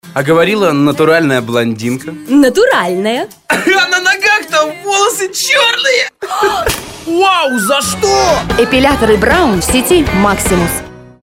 Игровой ролик (несколько актеров)